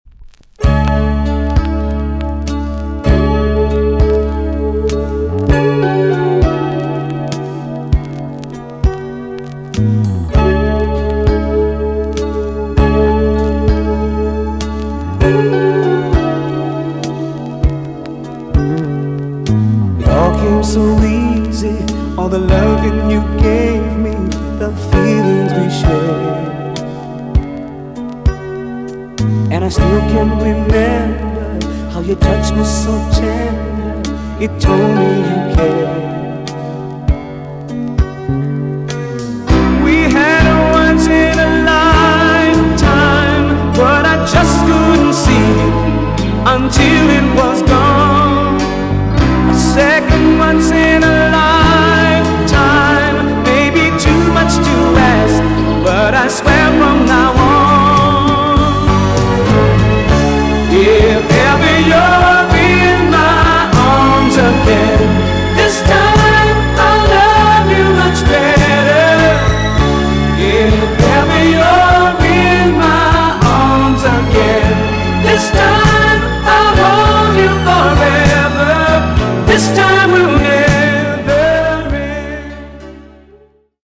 EX-~VG+ 少し軽いチリノイズが入りますが良好です。
1984 , WICKED JAMAICAN SOUL TUNE!!